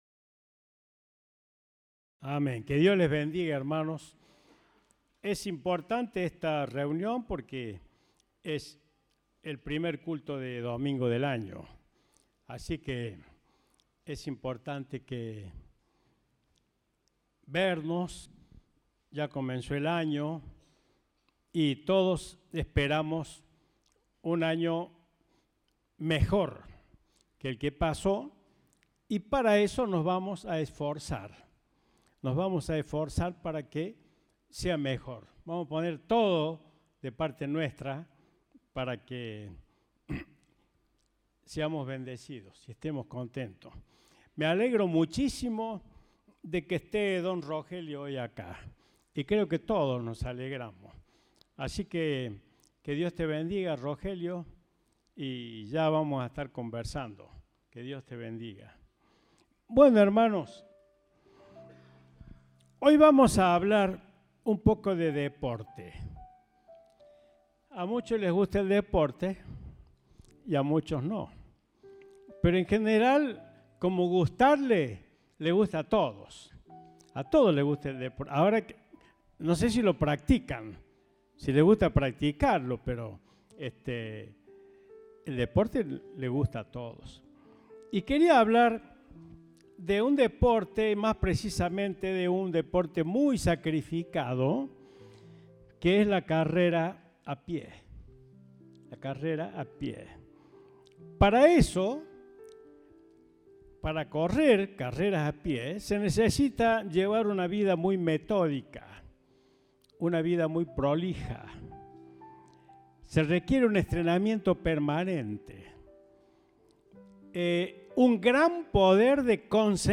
Compartimos el mensaje del Domingo 8 de Enero de 2023.